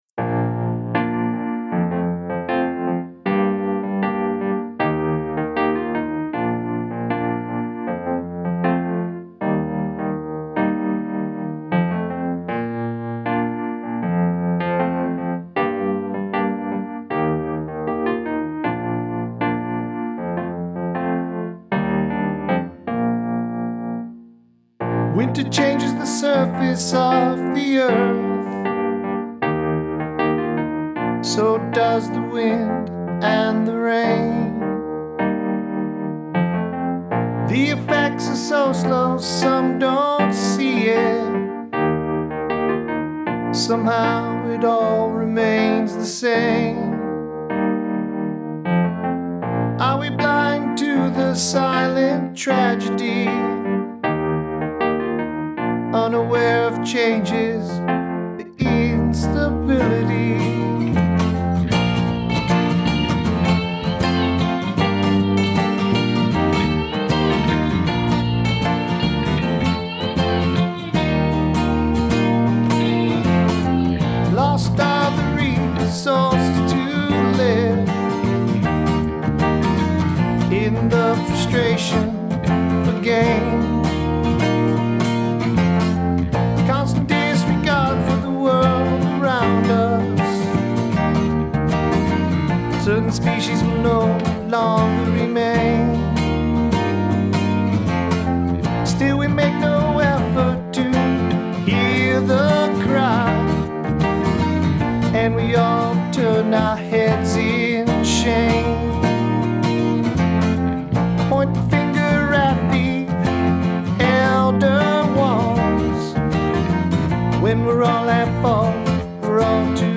Guitar/Vocals
Lead Vocals
Drums
Bass
Keys